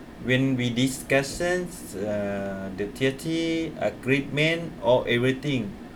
S1 = Brunei female S2 = Laos male
S1 subsequently guessed that it was treaty after listening to it carefully; but she could not understand it at the time. Discussion: The word is pronounced as [tɪəti] with no [r] and [ɪə] instead of [i:].